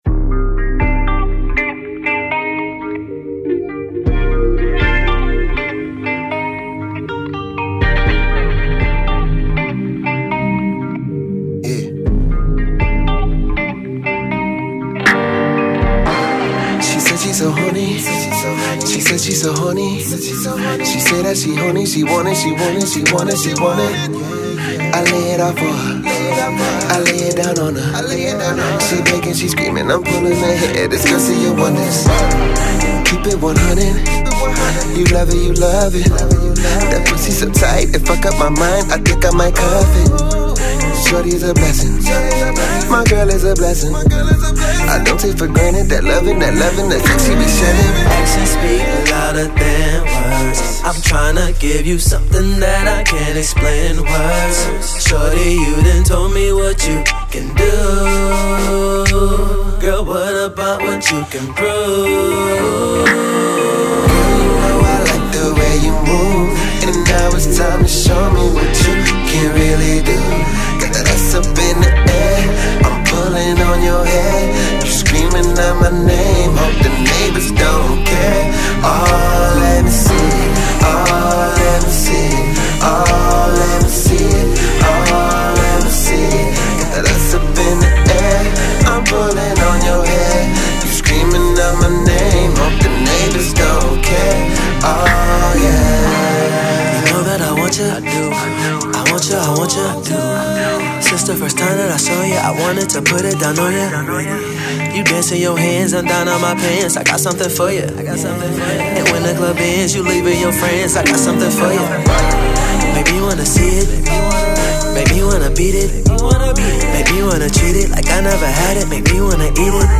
RnB
R&B duo